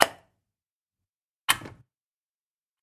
Звуки розетки, выключателей